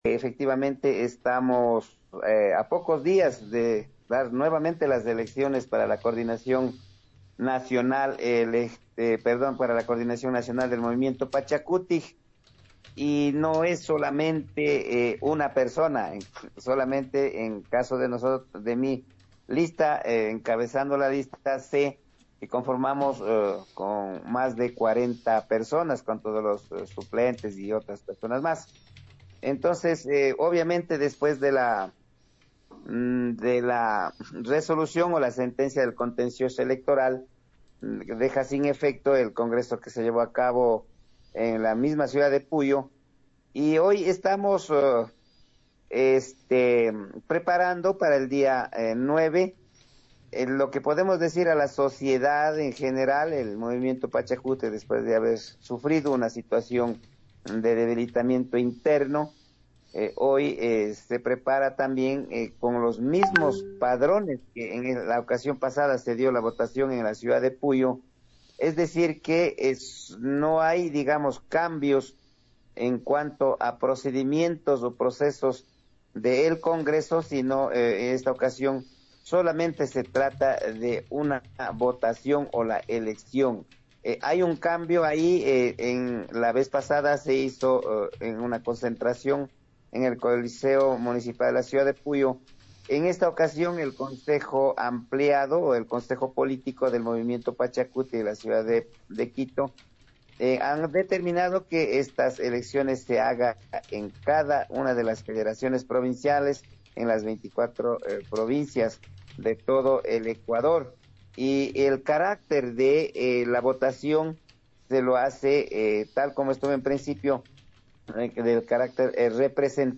en entrevista en Nina Radio 104.7 F. M. de Puyo